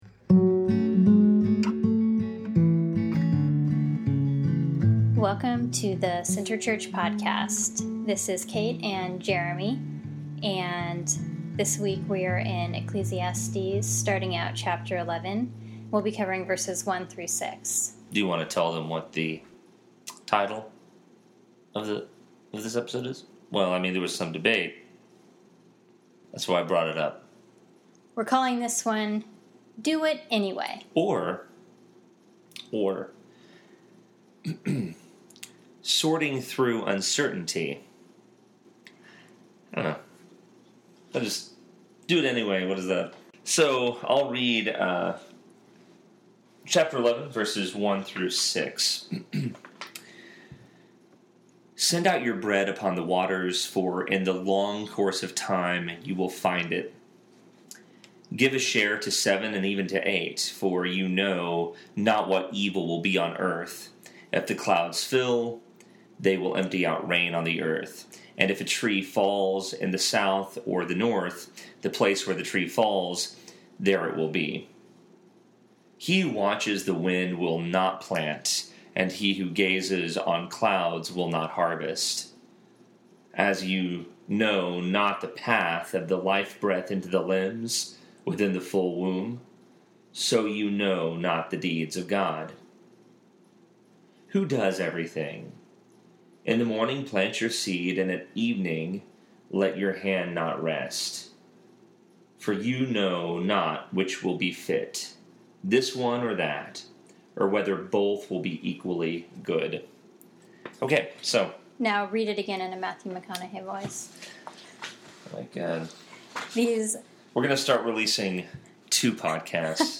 This sermon covers Ecclesiastes 11:1-6.